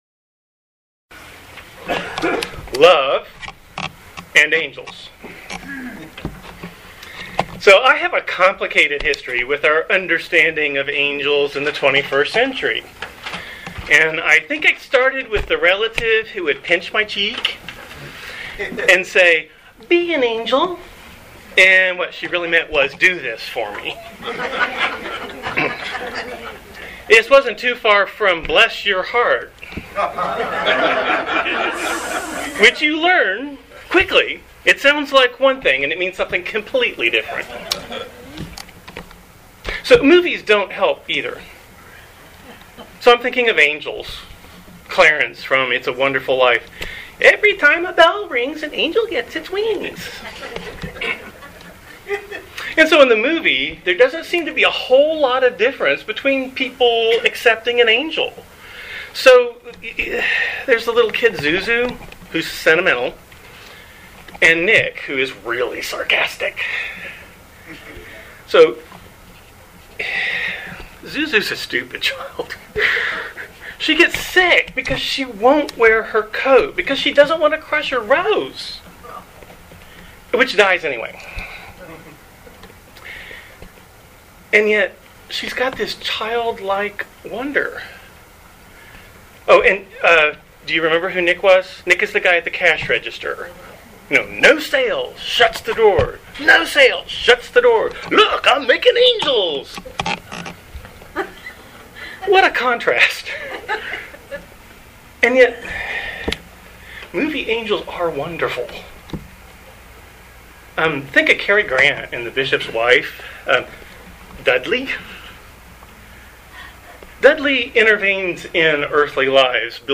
Living Beatitudes Community Homilies: Love and Angels